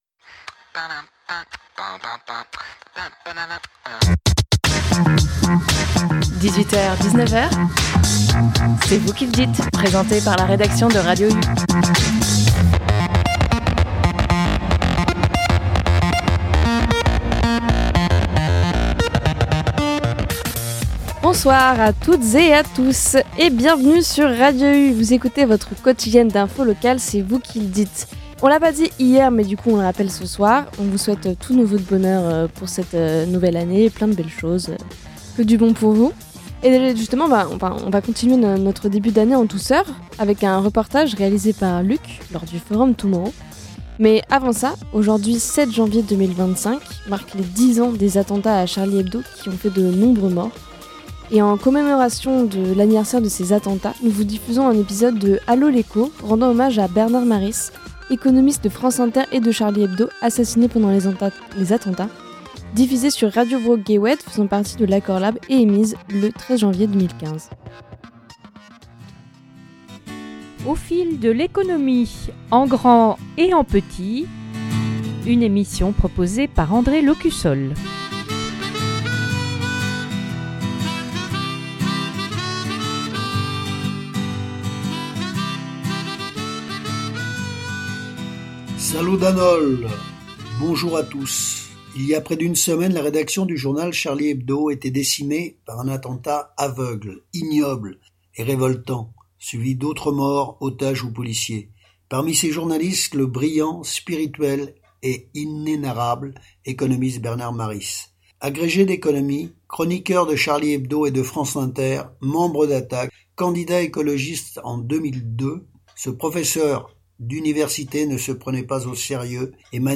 En seconde partie d’émission, une série d’interviews réalisées au forum Tomorrow qui s’est tenu le 19 décembre dernier.